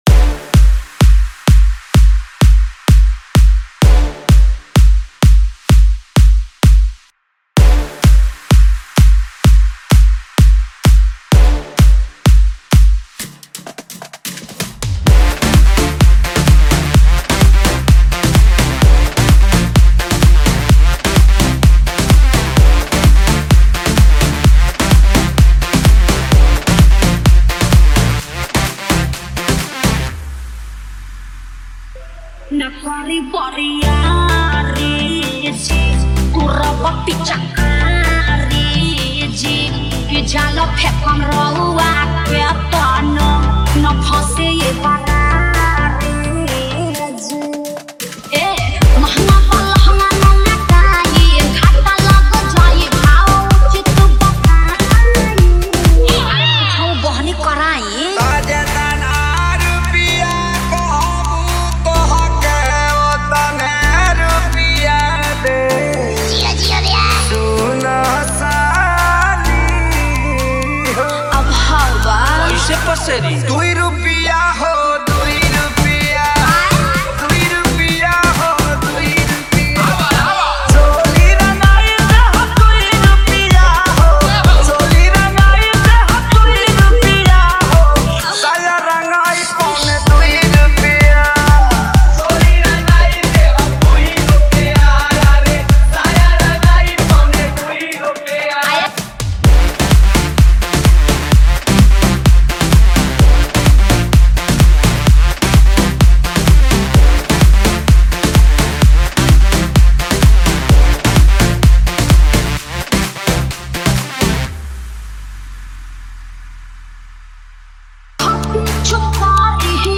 Bhojpuri DJ Remix Songs